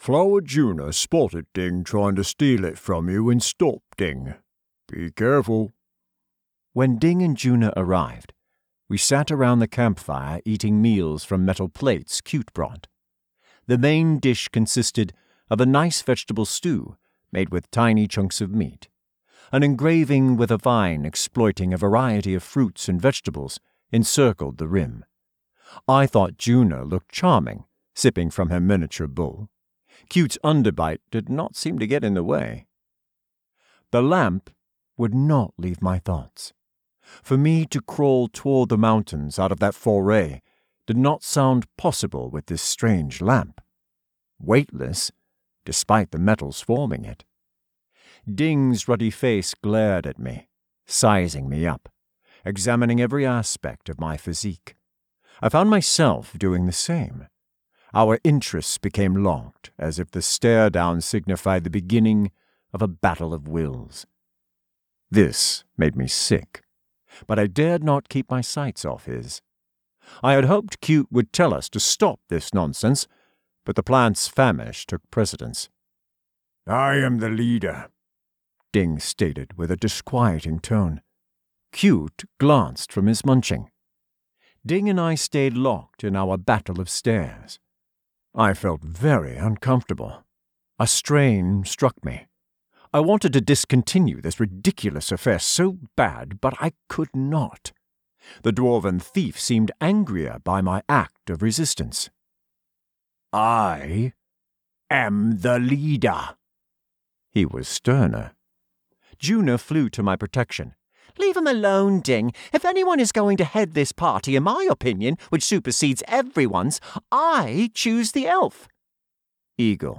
Audiobook Sample
His vocal ballet blows me away. In this sample, we'll meet the series' core characters, starting with Giants' Prince Kute speaking (pictured below). It's a pretty funny scene in parts.